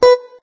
note_beepy_7.ogg